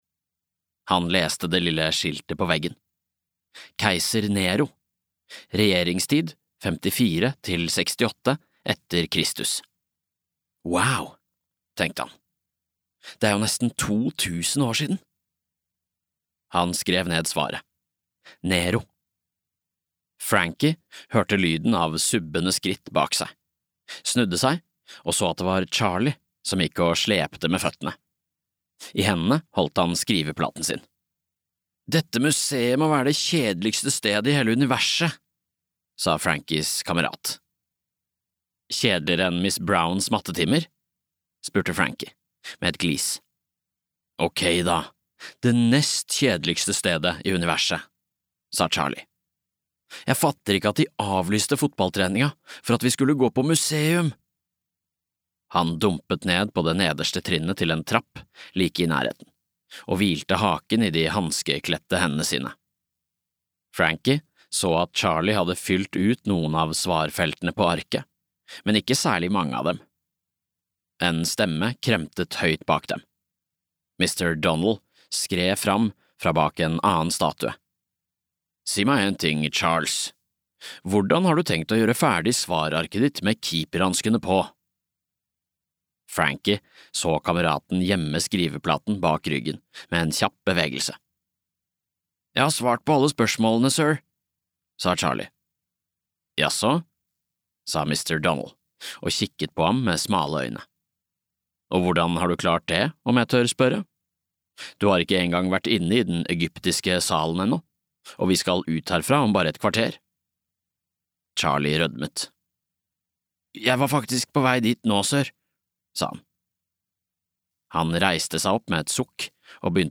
Frankie vs. Mumie FK (lydbok) av Frank Lampard